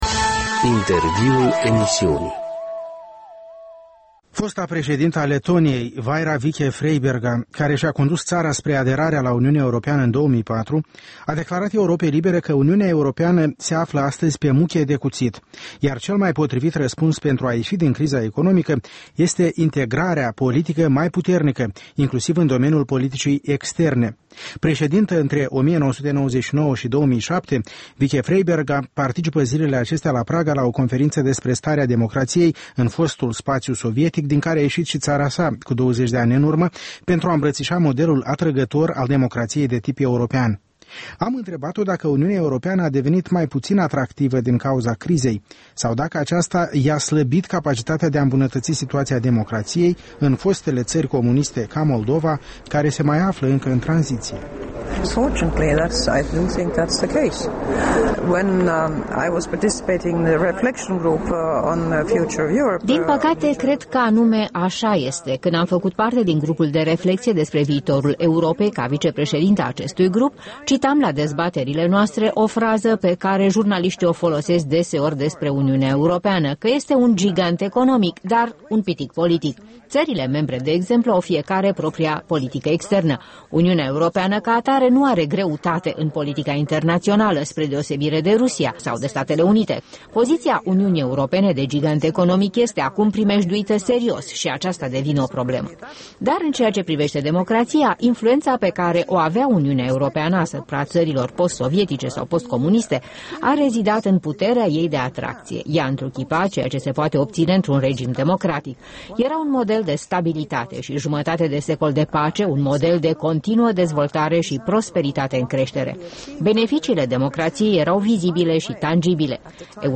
Un interviu cu Vaira Vike-Freiberga, fosta președintă a Letoniei